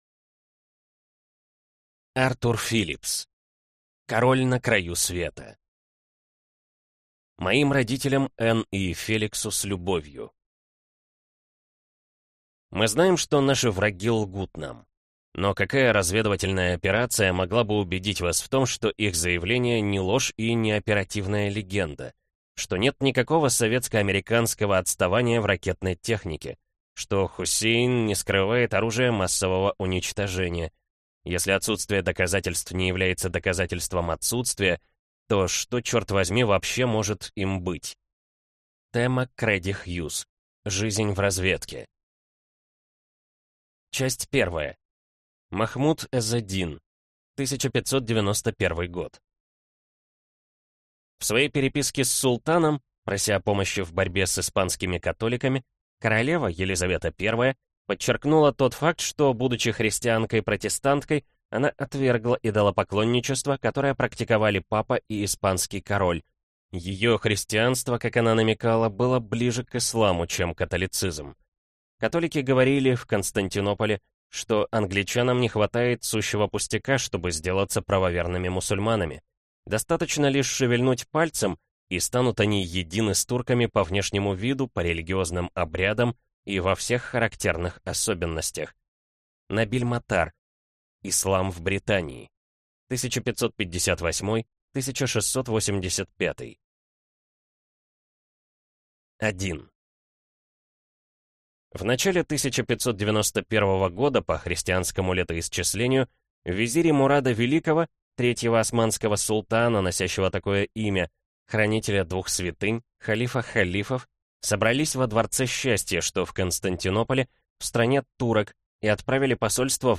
Аудиокнига Король на краю света | Библиотека аудиокниг